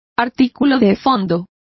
Complete with pronunciation of the translation of editorial.